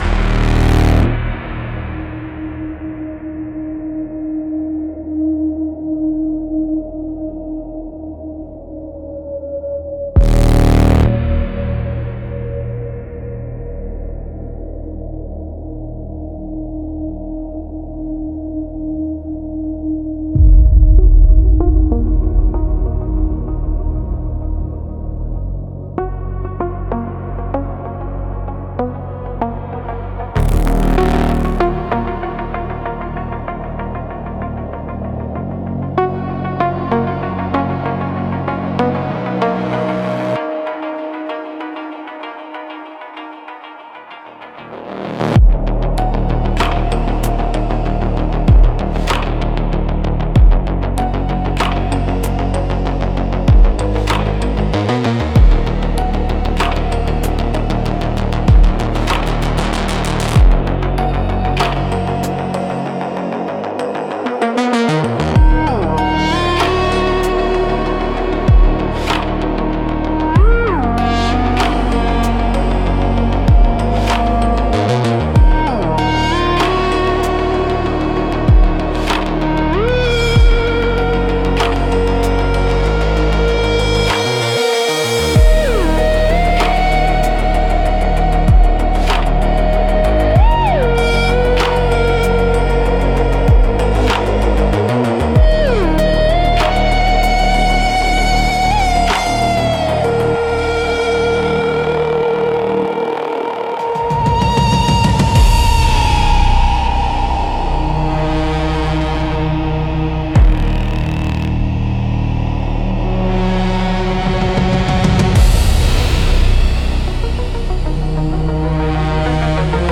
Instrumentals - Where Light Goes to Fracture